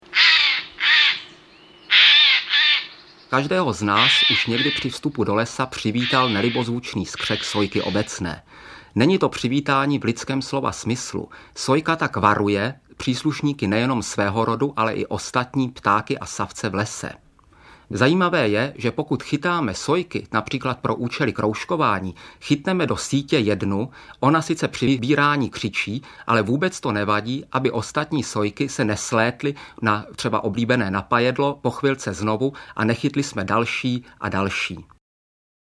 Komentované nahrávky ptáků 40 druhů nejběžnějších a nejzajímavějších ptáků žijících v našich lesích.